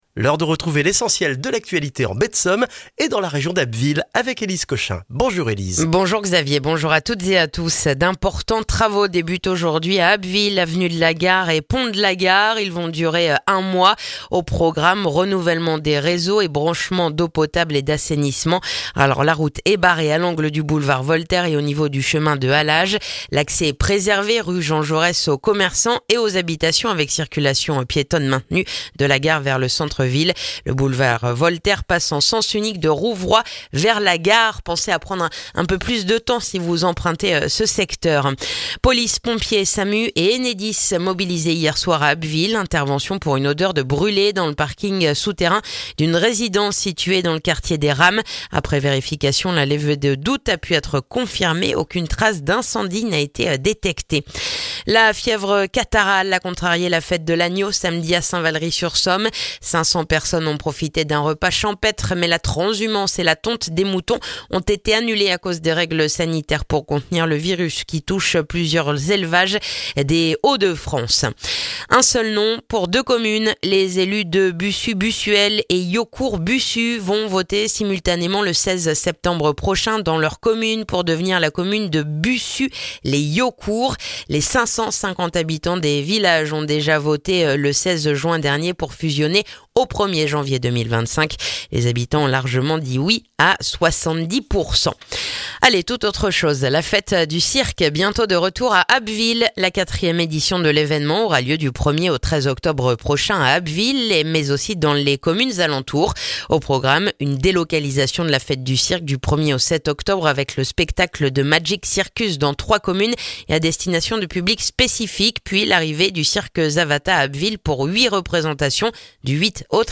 Le journal du lundi 9 septembre en Baie de Somme et dans la région d'Abbeville